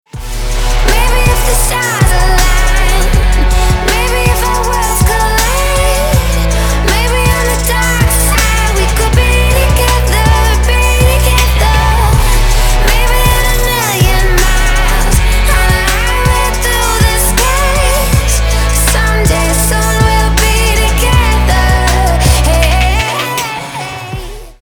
• Качество: 320, Stereo
поп
женский вокал
мелодичные